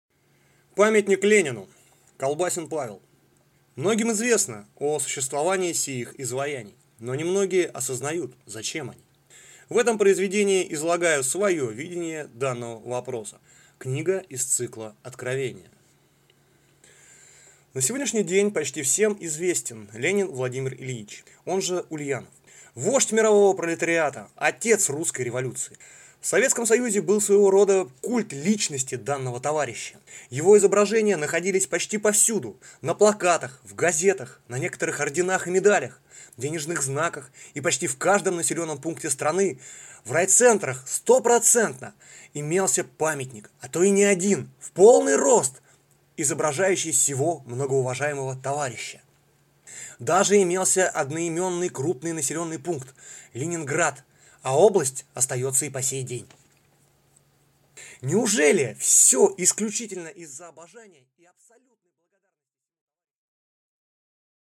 Аудиокнига Памятник Ленину | Библиотека аудиокниг